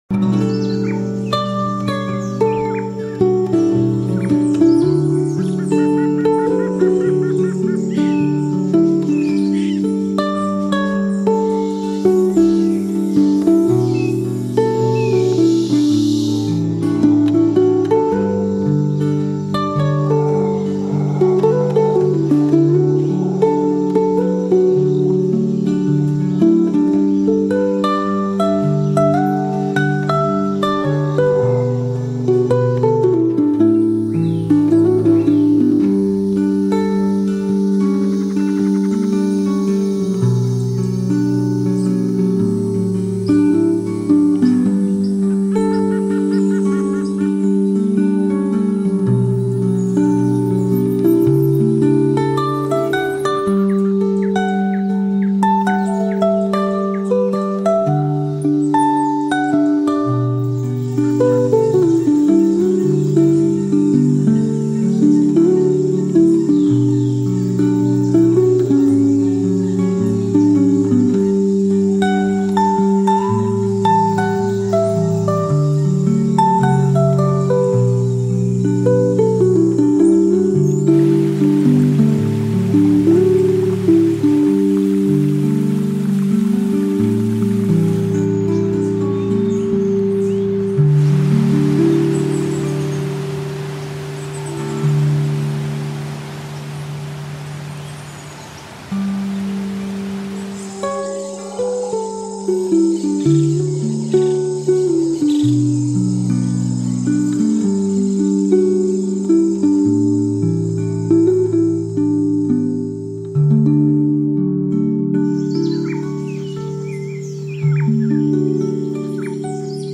For Peace & Gentle Relaxation
warm and gentle acoustic guitar piece